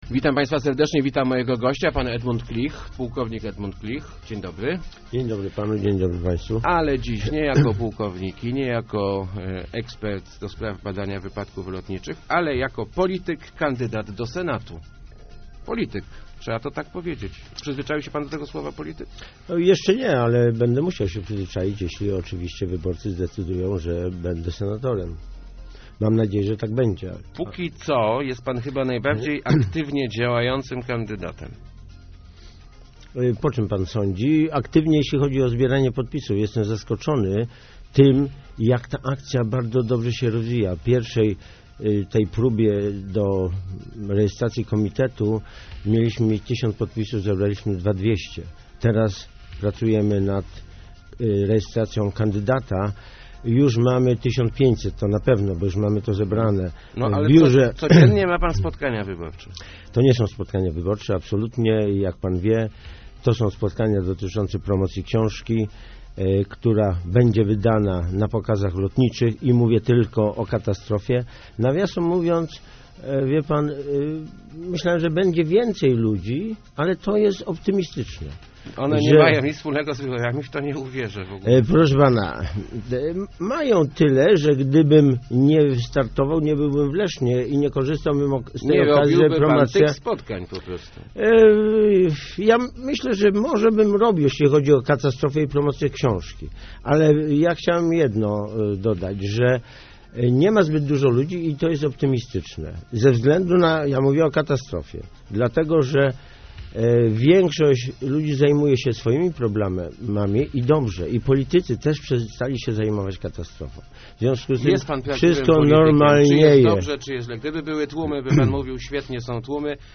Będziemy niepokornym Senatem, który rozbije partyjny monolit - mówił w Rozmowach Elki Edmund Klich, niezależny kandydat na senatora.